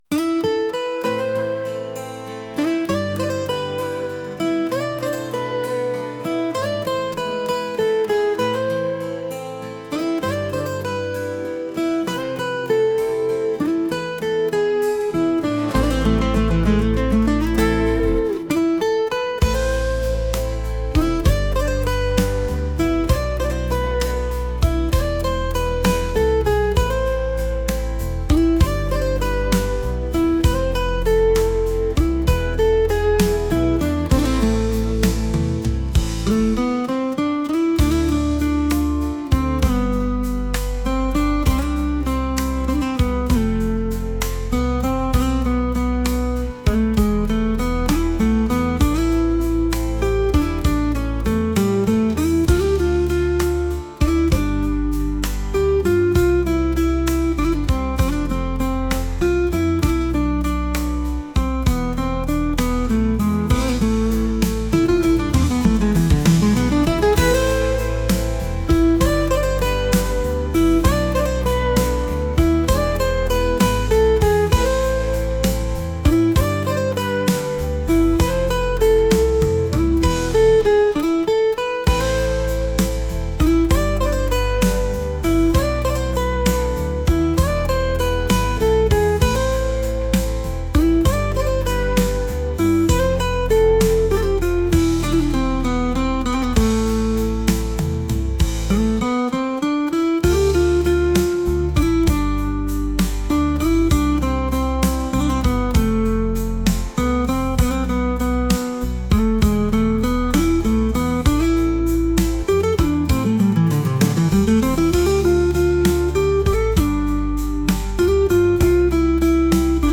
• Жанр: Детские песни
Нежная мелодия для романтического настроения.
Медленные гитарные композиции для души.